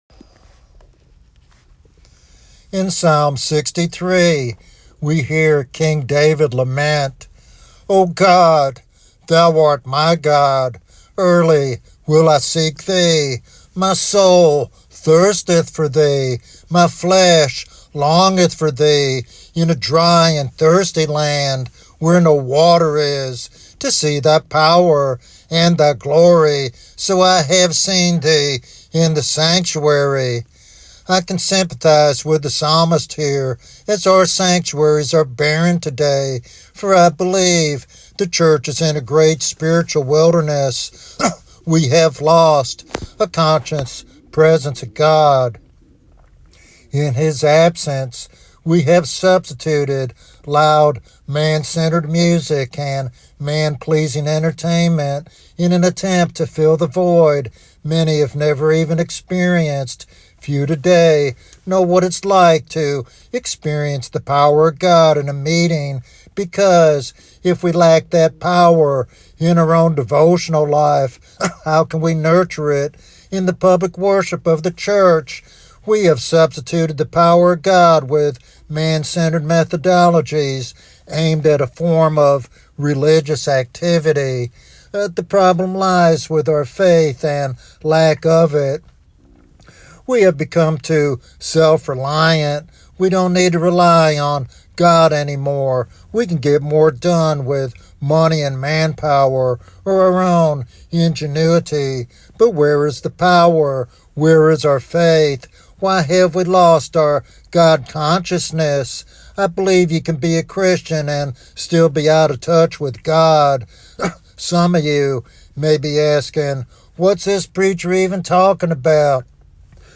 This sermon offers practical steps to nurture a living relationship with God and experience His manifest presence, which is essential for revival and effective Christian service.